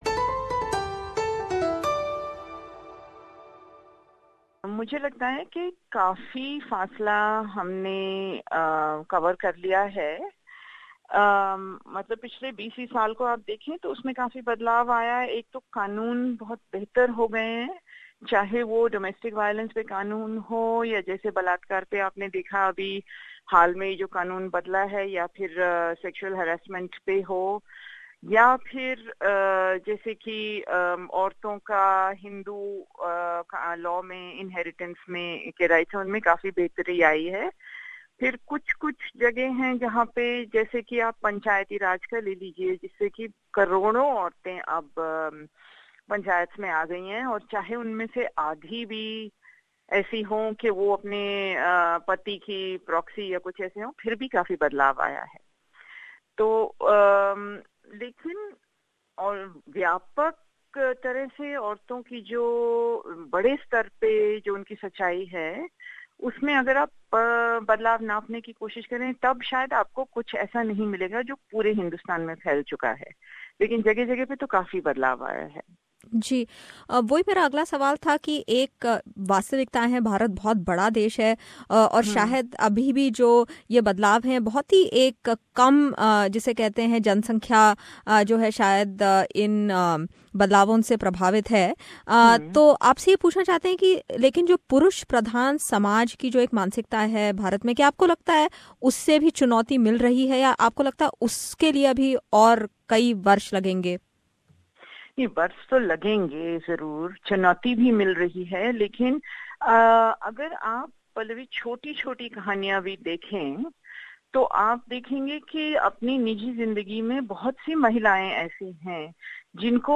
SBS Hindi caught up with one of India's most well known Feminist Urvashi Butalia who was recently in Australia for the Melbourne Writers Festival. Tune in for this free flowing chat with the well known Feminist and Author Urvashi Butalia.